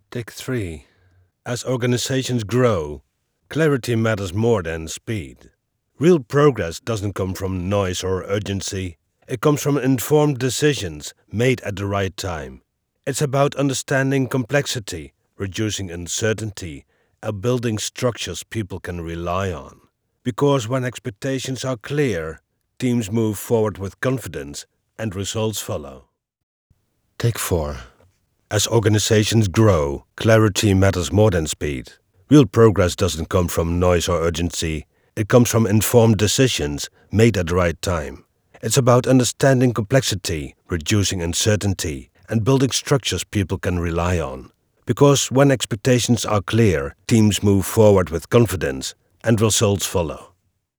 Native Dutch voice-over for international corporate and institutional communication, delivering calm, clear narration with a subtle European touch.
Middle Aged
I am a native Dutch voice-over, also recording in English with a subtle European (Dutch) accent.